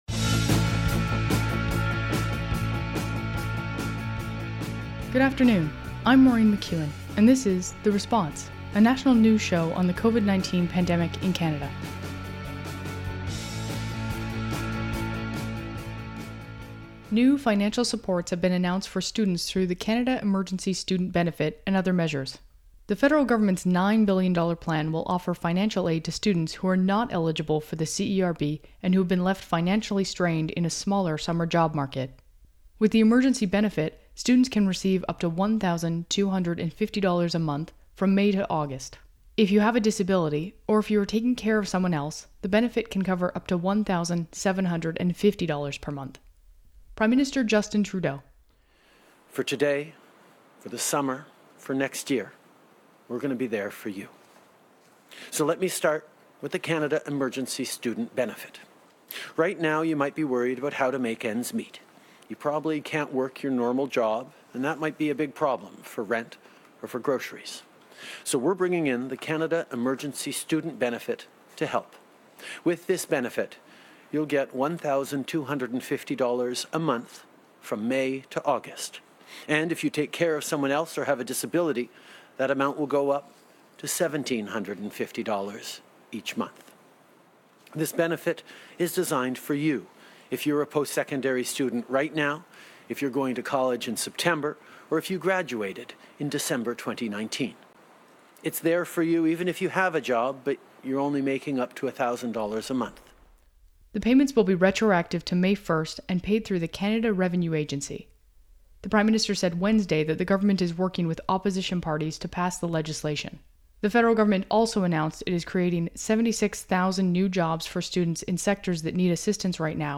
National News Show on COVID-19
Theme: "Headed South" by The Hours.
Credits: Audio clips: Canadian Public Affairs Channel.
Type: News Reports